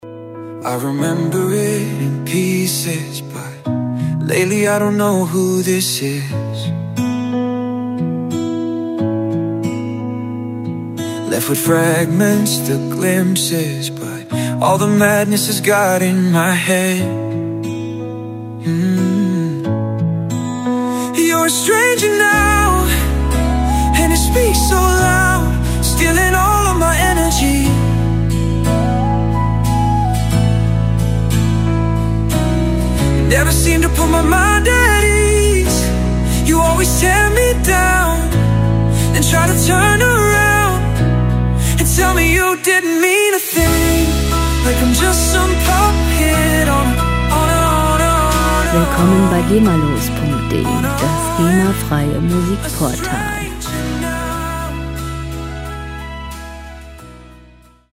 Rockmusik - Naturfreunde
Musikstil: Country Pop
Tempo: 90 bpm
Tonart: Cis-Moll
Charakter: kraftvoll, natürlich